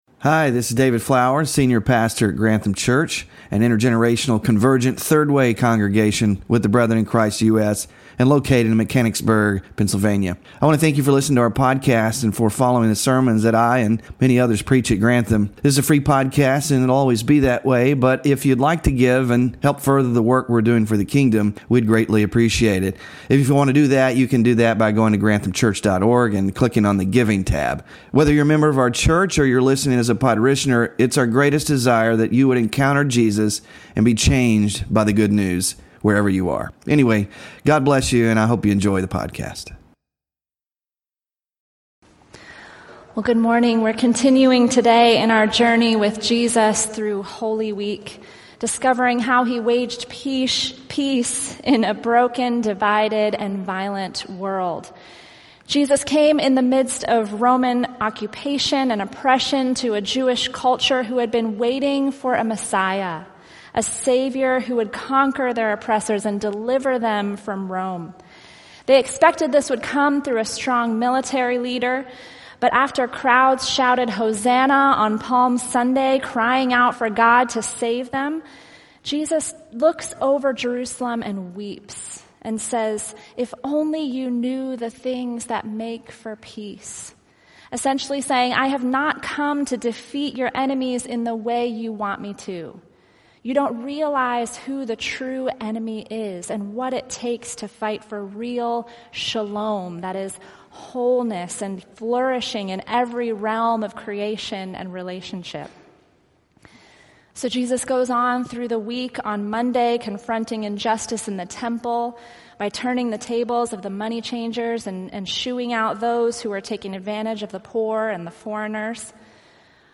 WORSHIP RESOURCES Waging Peace (4 of 7) Sermon Slides Small Group Discussion Questions